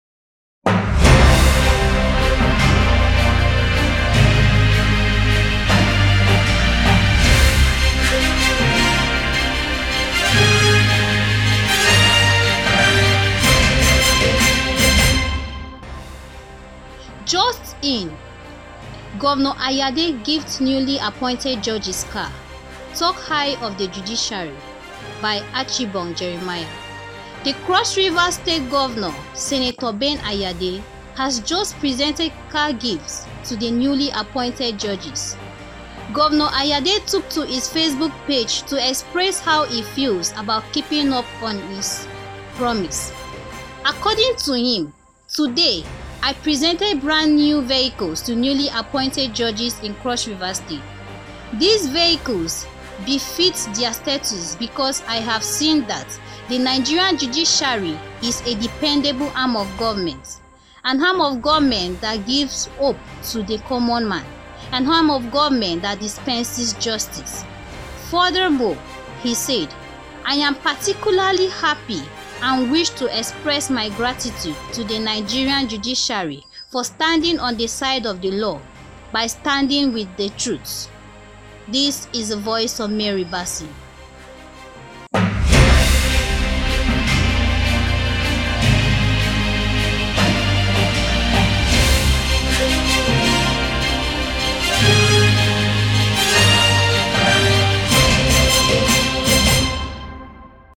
Breaking News Podcast Reports